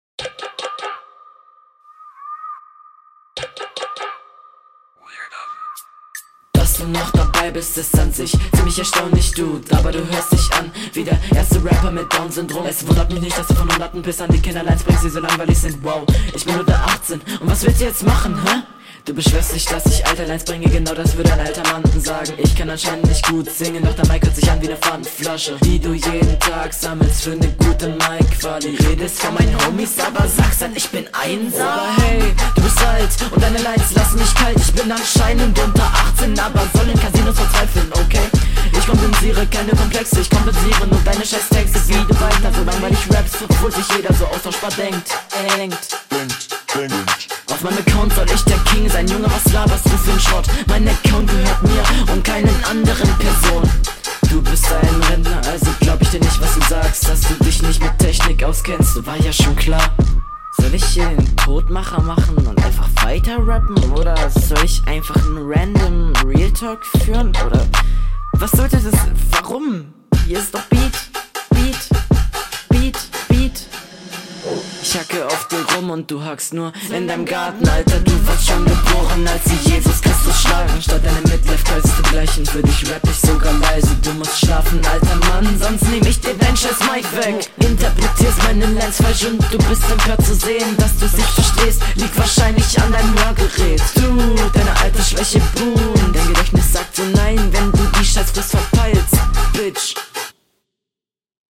Im Vergleich zur Hr wirkt deine Stimme etwas schwächer, aber die Klangfarbe ist generell angenehm.